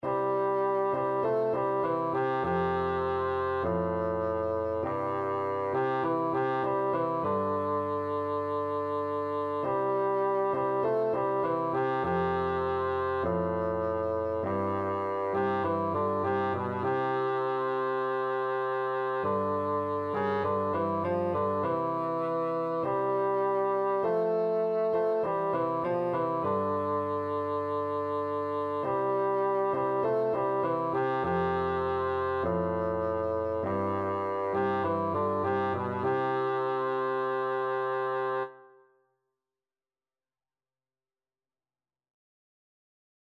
4/4 (View more 4/4 Music)
G3-A4
Classical (View more Classical Bassoon Music)